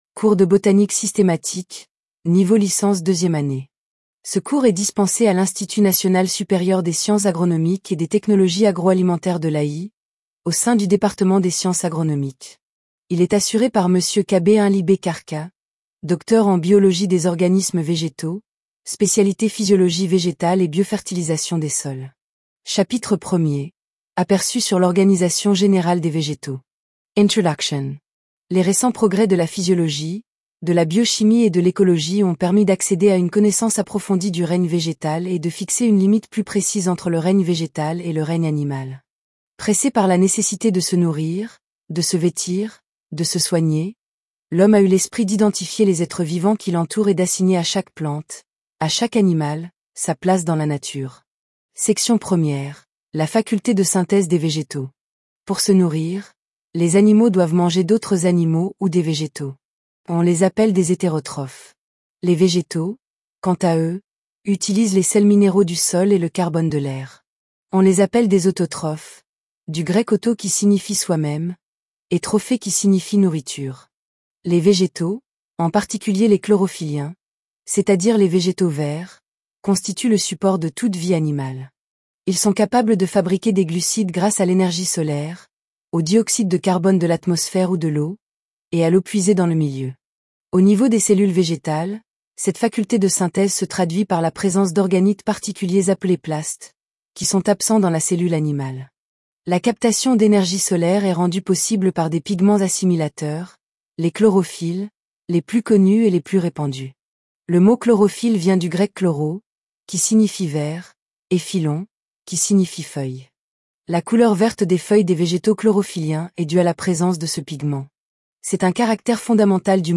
Podcast : Cours Botanique Systematique Tts
cours_botanique_systematique_TTS.mp3